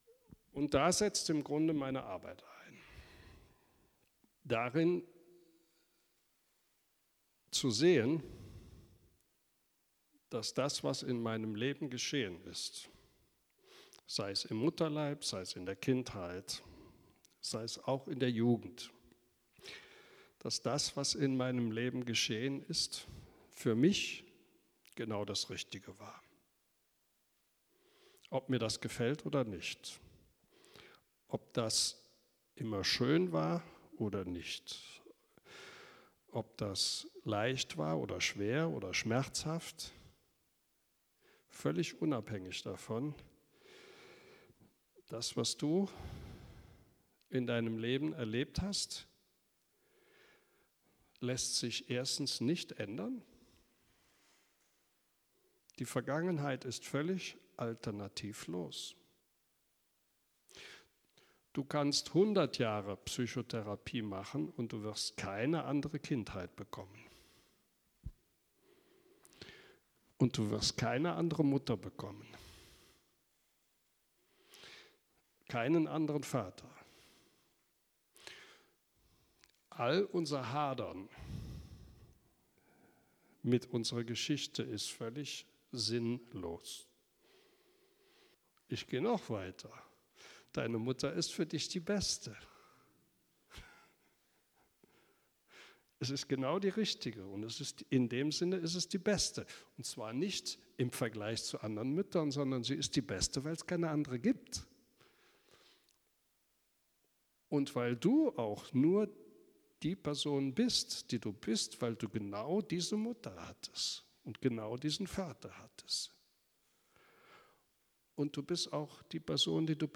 Audio-Vorträge